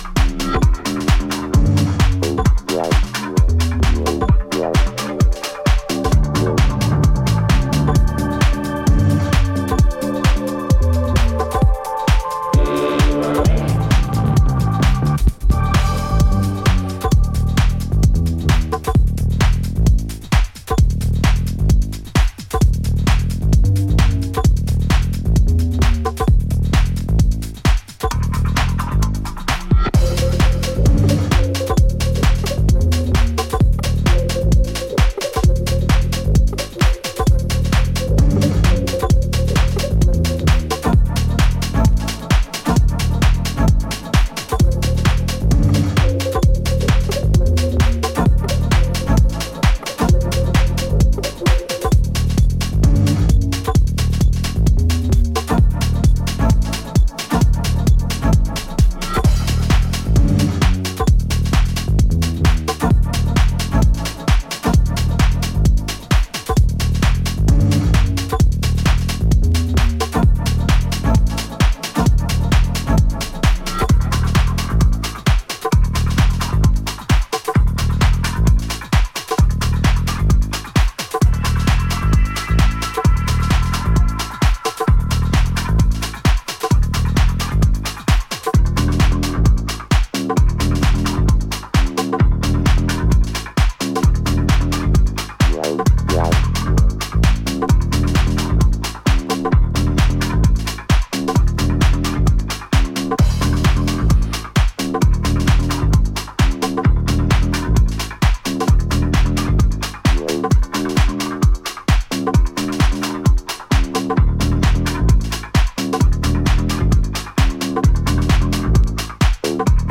燻んだ音像でブーストするベースラインや凶悪な鳴りのシンセリフ等